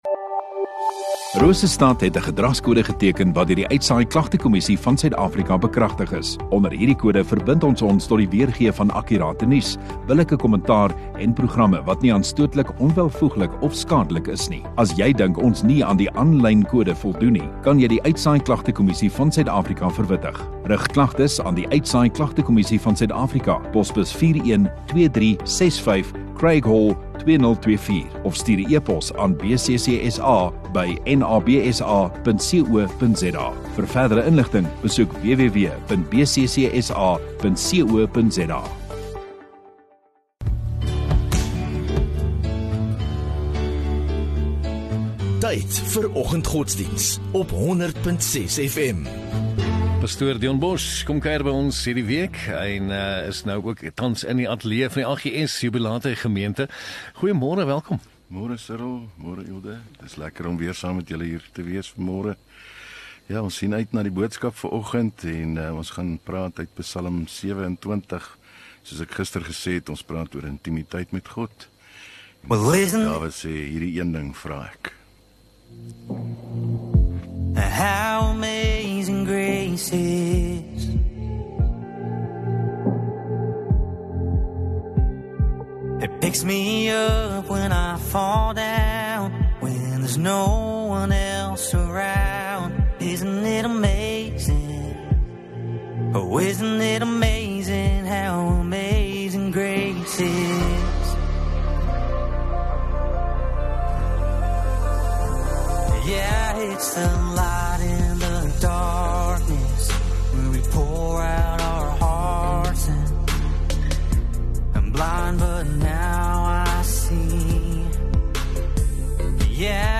25 Jun Dinsdag Oggenddiens